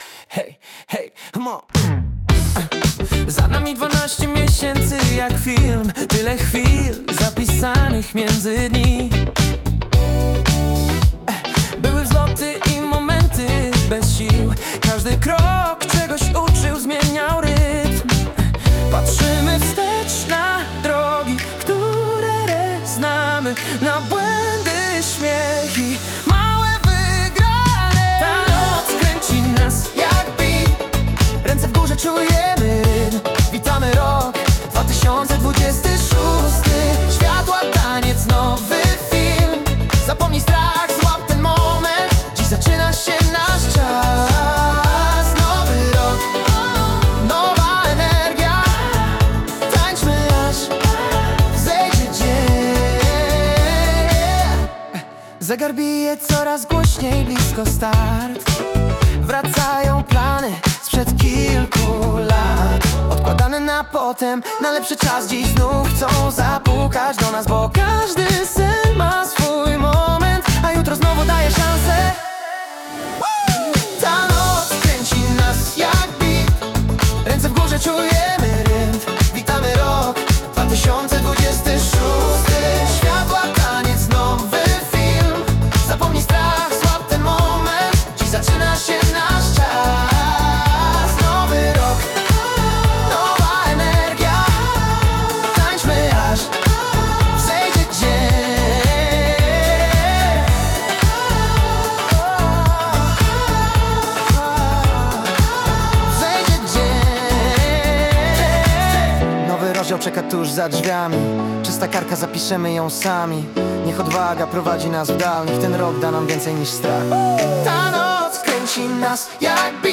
pop-funky z radiowym brzmieniem